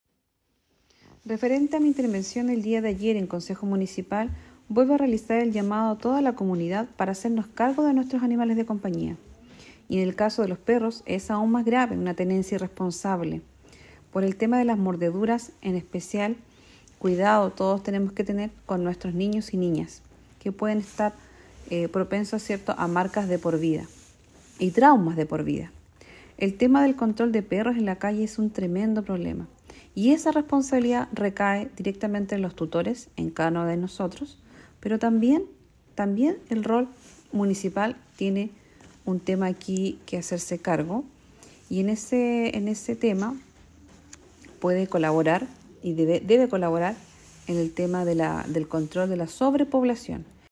Durante la última sesión ordinaria del Concejo Municipal de Osorno, realizada el pasado martes, la concejala Cecilia Canales realizó una intervención para solicitar la aplicación de un protocolo de emergencia ante la tenencia responsable.
30-junio-22-cecilia-canales-intervencion.mp3